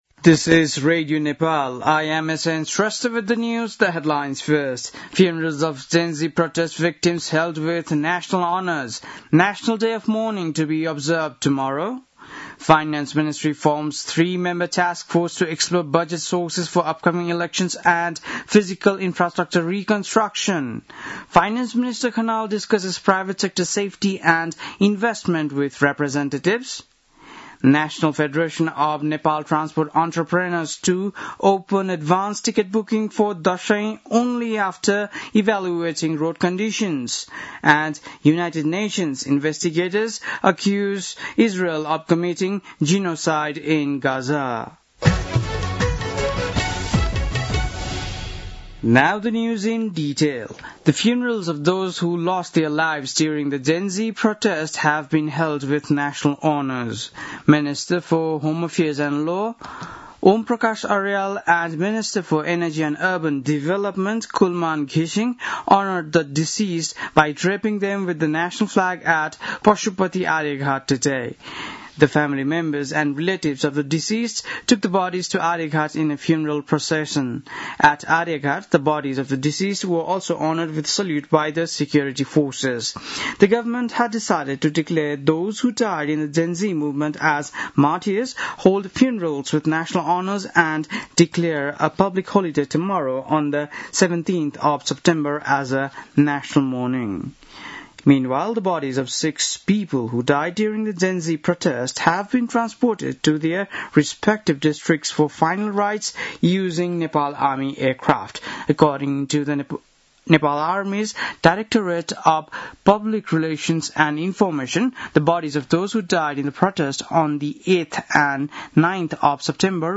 बेलुकी ८ बजेको अङ्ग्रेजी समाचार : ३१ भदौ , २०८२